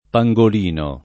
pangolino [ pa jg ol & no ]